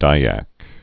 (dīăk)